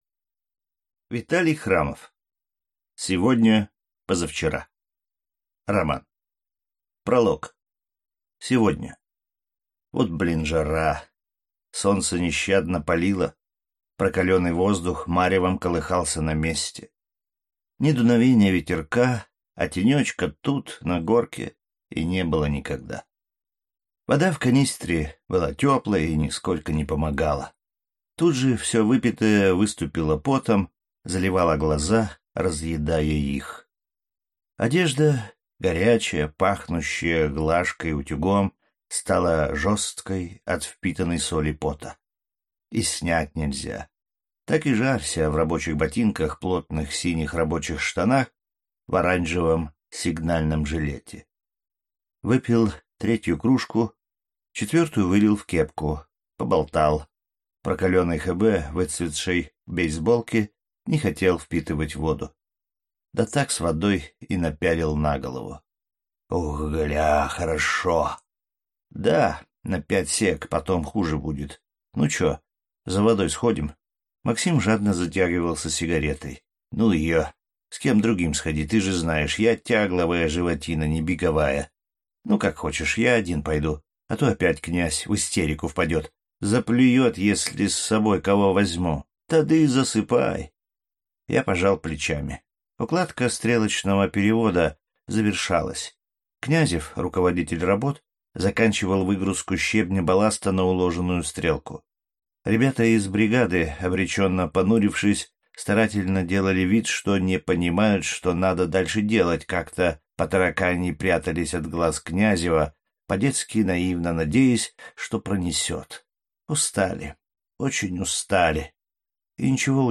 Аудиокнига Сегодня – позавчера | Библиотека аудиокниг